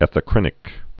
(ĕthə-krĭnĭk)